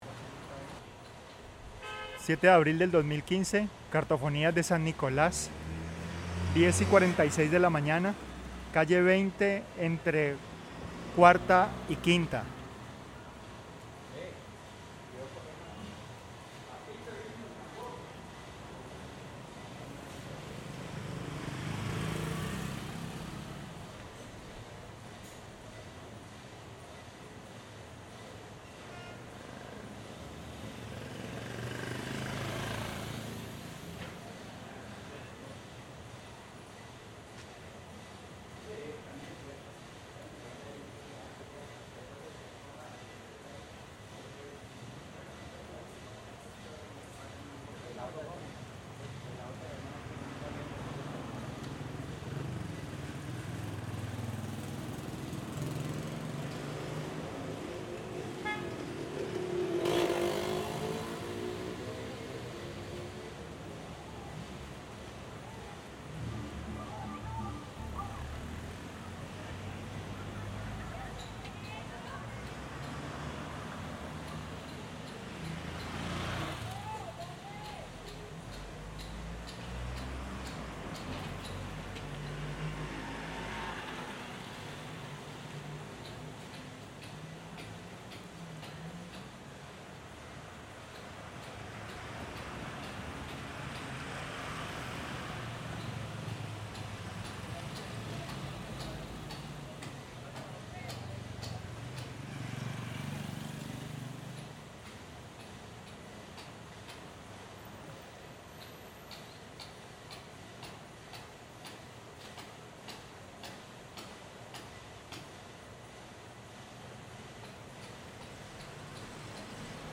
Audio 7 (Calle 20 entre Carreras 4 y 5 - abril 7 martes).mp3 Resumen Descripción Grabación del barrio San Nicolás. Hace parte de Cartofonías de San Nicolás Estudios sobre la memoria sonora de la industria gráfica en Cali.